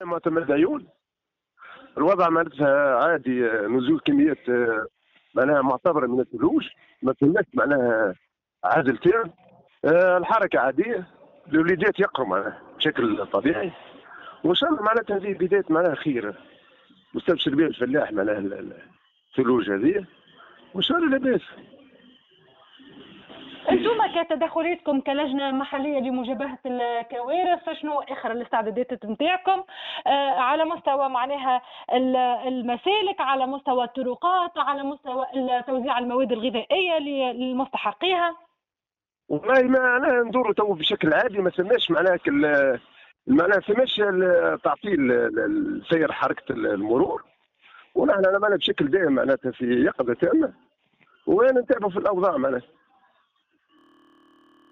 وفريد ربيع معتمد العيون:
فريد-ربيع-معتمد-العيون-.mp3